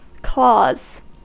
Ääntäminen
Ääntäminen US Tuntematon aksentti: IPA : /klɔːz/ Lyhenteet (laki) cl.